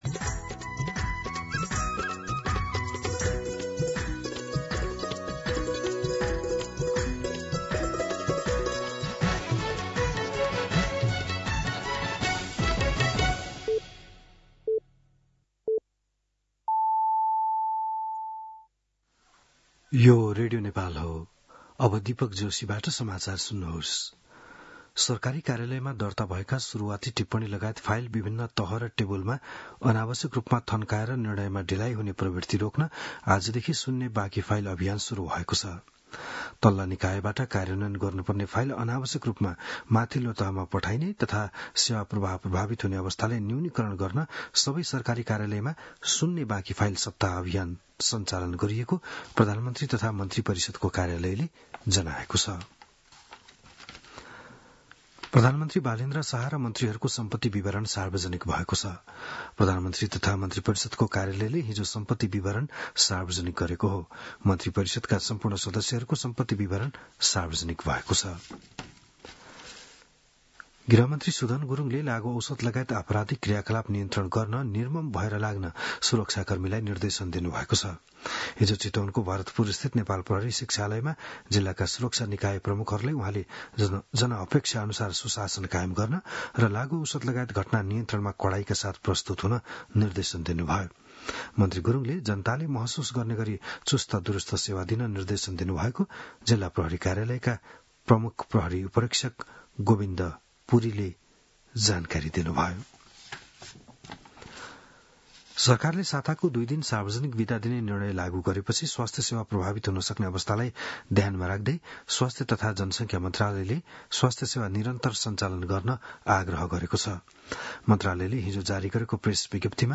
बिहान ११ बजेको नेपाली समाचार : ३० चैत , २०८२